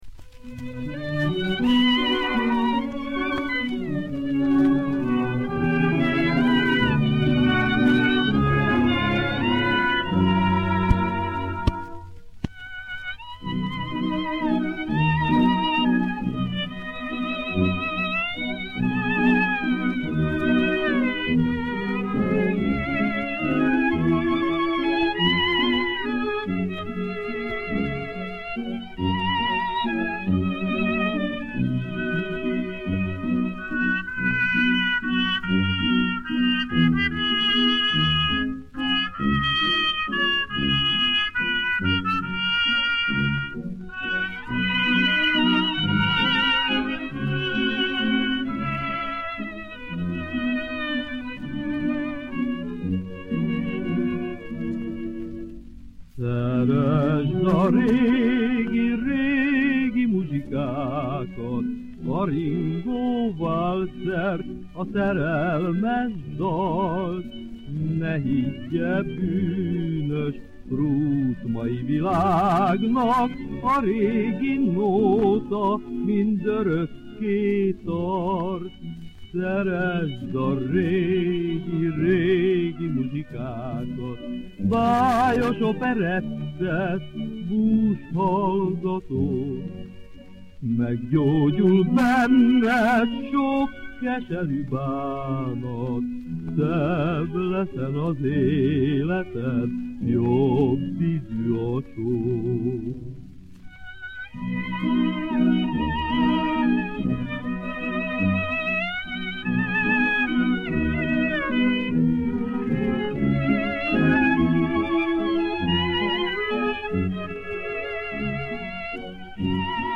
magyar kabaré- és jazz-énekes